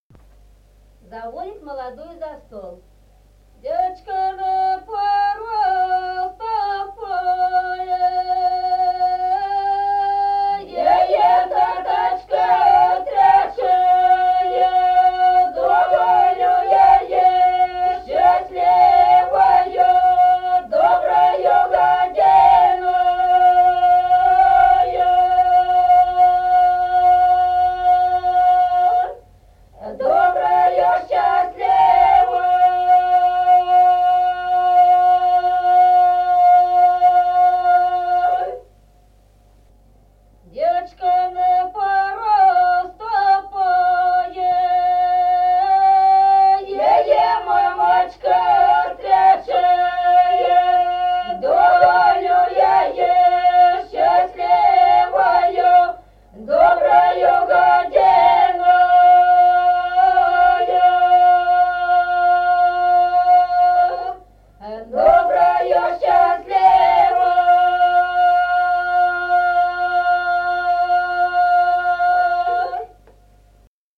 Песни села Остроглядово. Девочка на порог ступае.